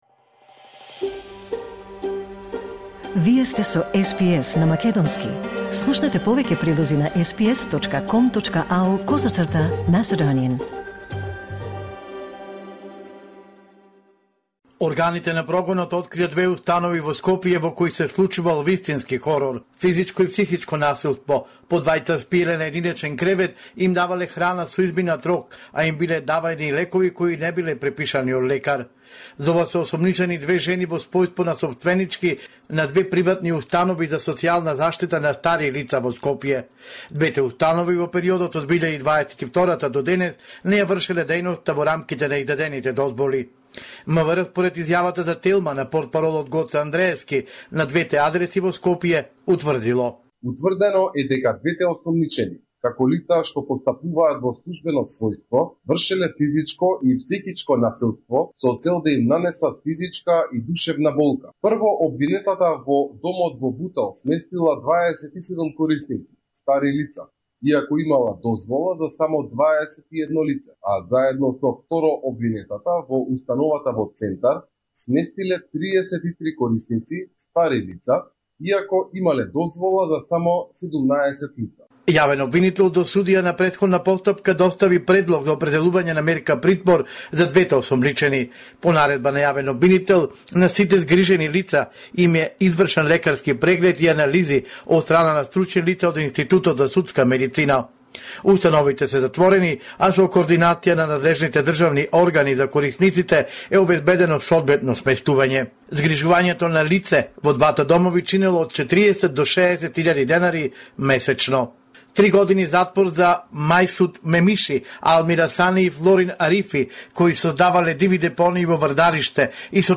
Извештај од Македонија 9 окомври 2025